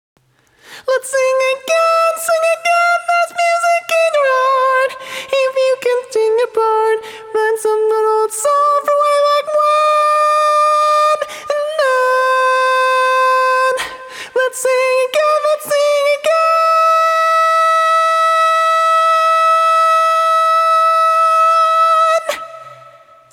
Key written in: C Major
Type: Female Barbershop (incl. SAI, HI, etc)
Each recording below is single part only.
Learning tracks sung by